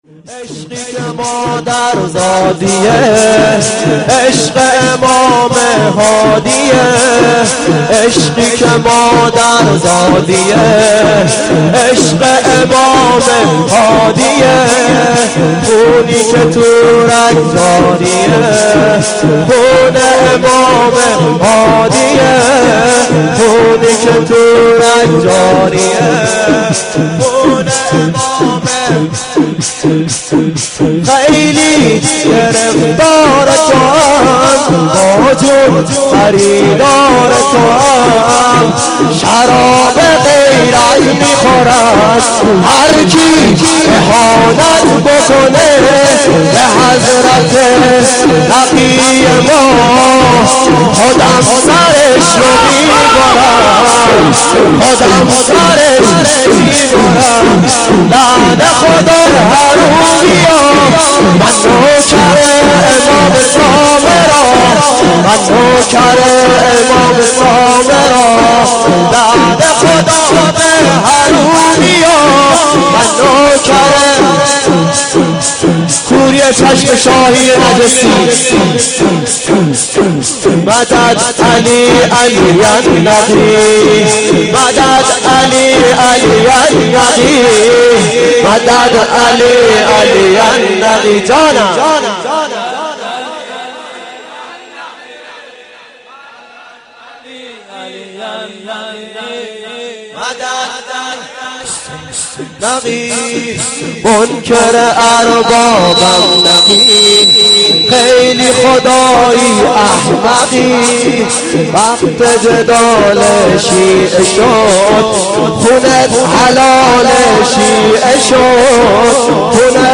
مراسم شهادت امام هادی (علیه السلام)
در شب شهادت آقا علی النقی (علیه السلام) و به میزبانی هیئت محبین الحسن (علیه السلام) برگزار شد.
عشقی که مادر زادیه.../شور